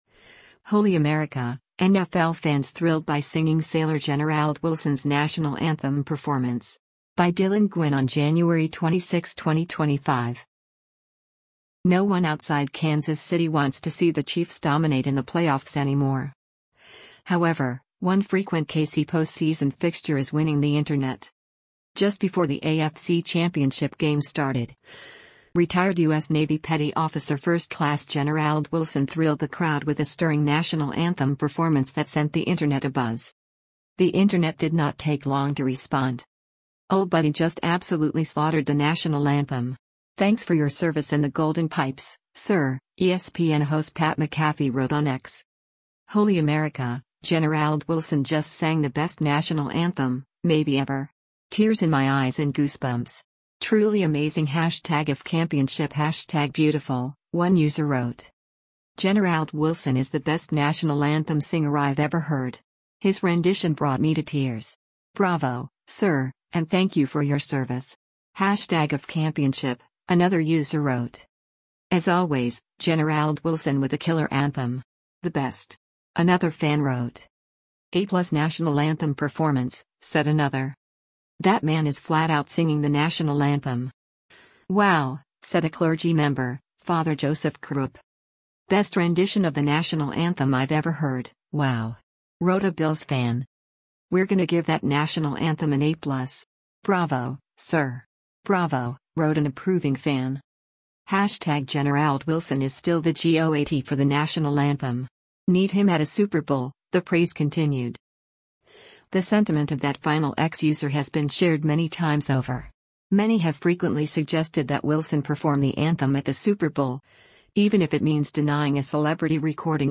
Just before the AFC Championship Game started
thrilled the crowd with a stirring national anthem performance
“A+ National Anthem performance,” said another.